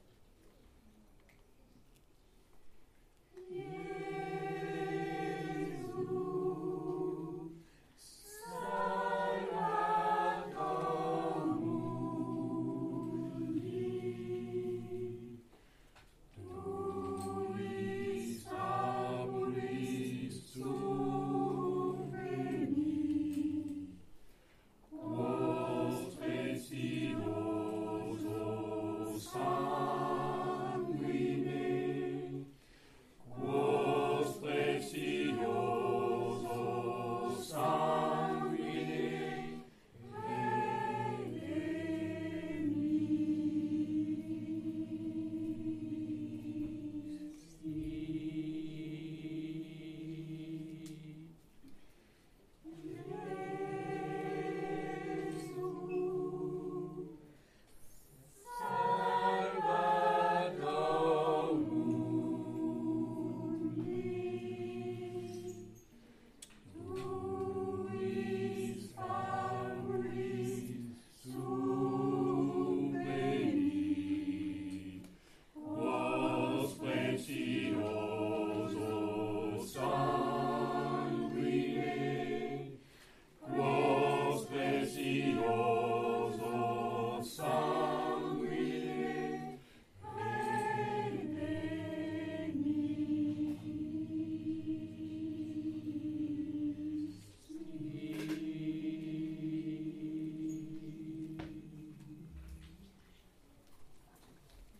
Cess'tival 2025 le 21 juin à Cesson-Sévigné à la médiathèque du Pont des Arts